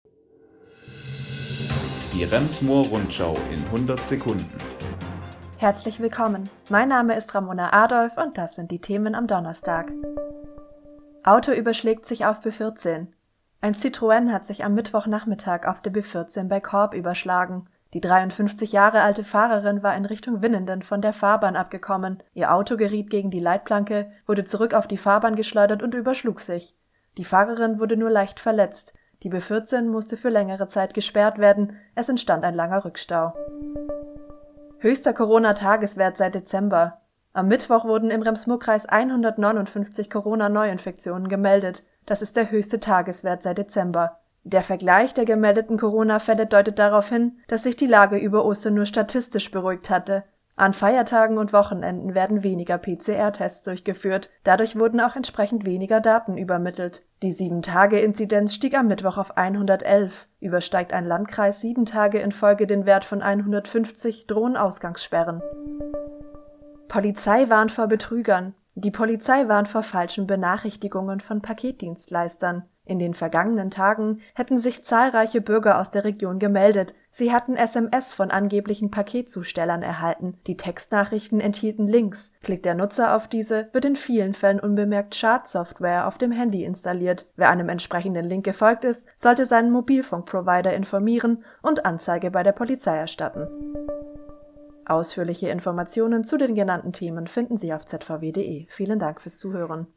Die wichtigsten Nachrichten des Tages